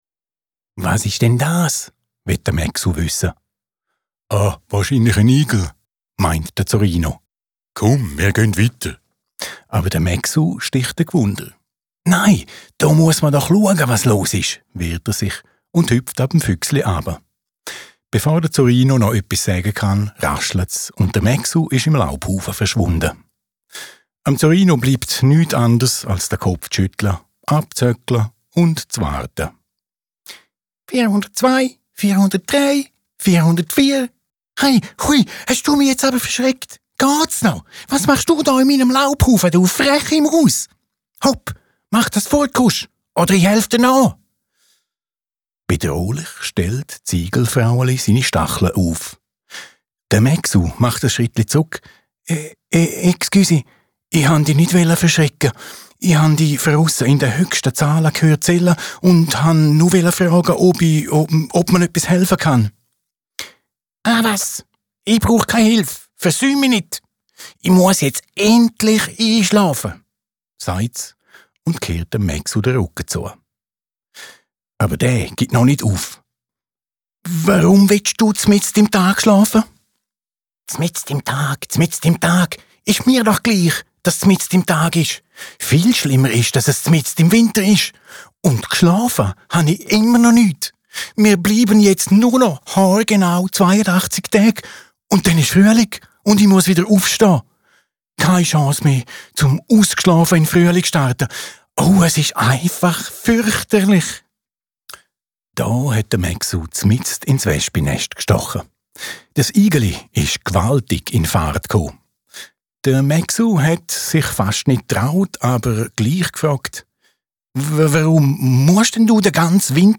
Hörproben.